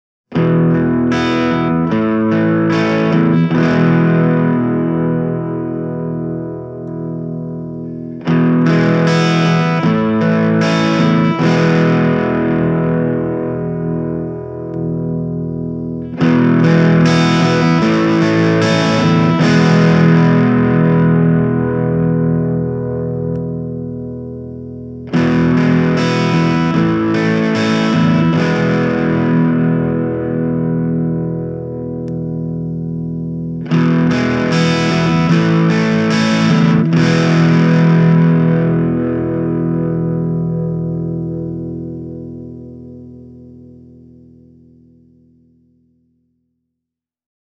The Pre Amp side of things sports a Bright-switch for adding sparkle to your top end, as well as a Fat-switch that will boost the bass response.
Listen to these two sound clips – clean and crunch – to get an idea of how the Valve Stage switches change the combo’s sound (Gibson Les Paul Junior, Shure SM57). Both clips start with all the switches in the left position.